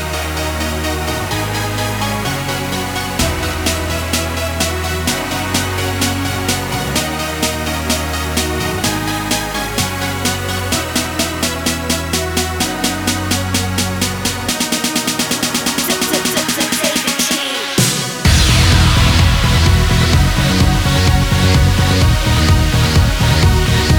no Backing Vocals Dance 3:29 Buy £1.50